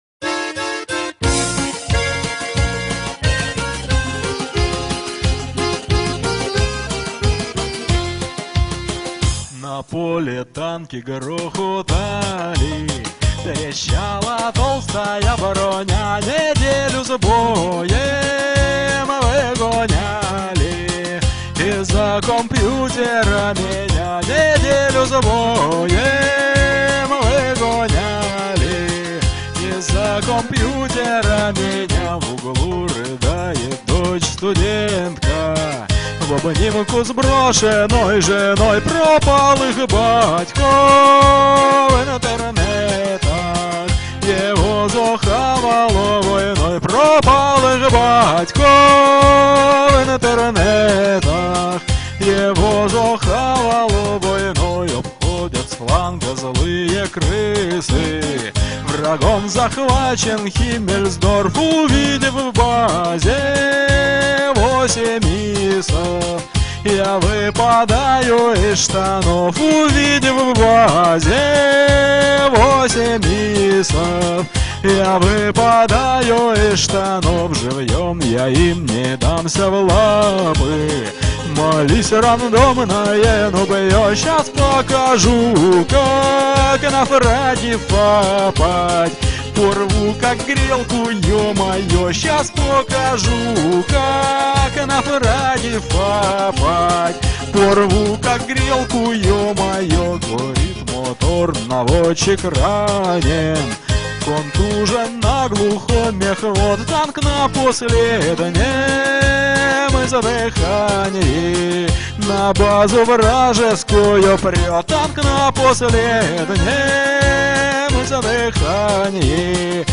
Веселая песенка